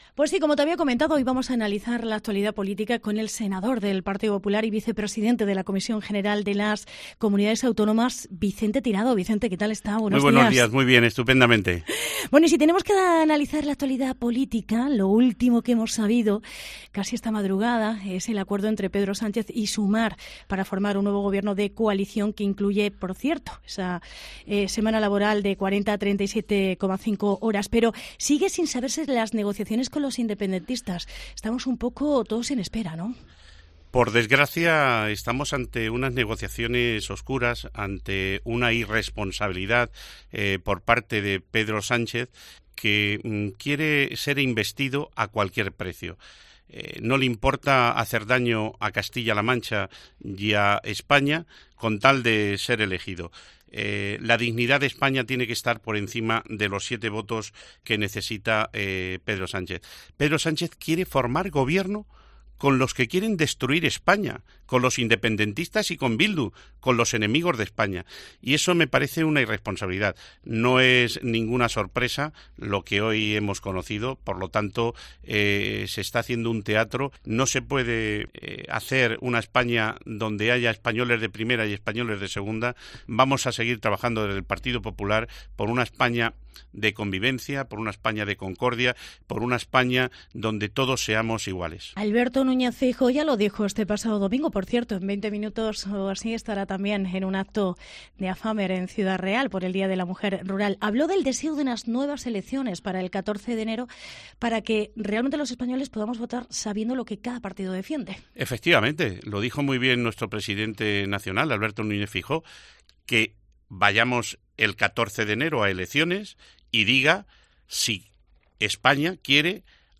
Declaraciones en Herrera en COPE Castilla-La Mancha sobre el aumento de los 33 diputados a un máximo de 59 que contempla la reforma del Estatuto de CLM